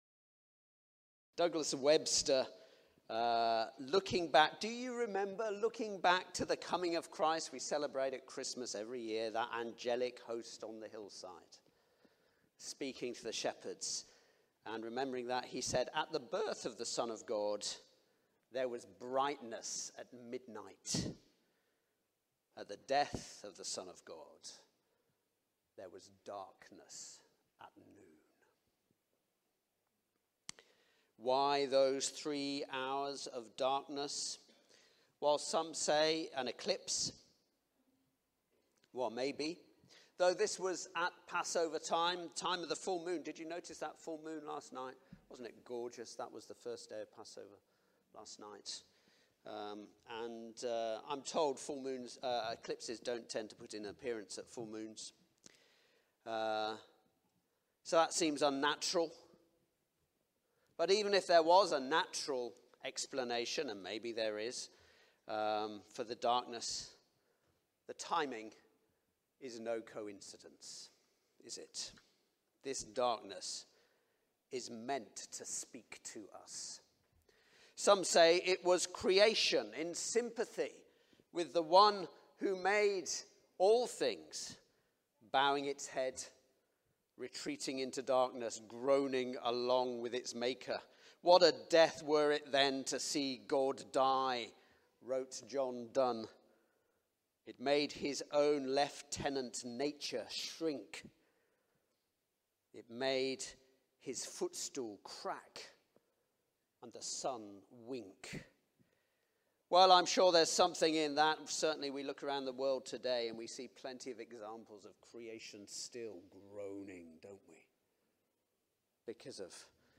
Good-Friday-2023-sermon.mp3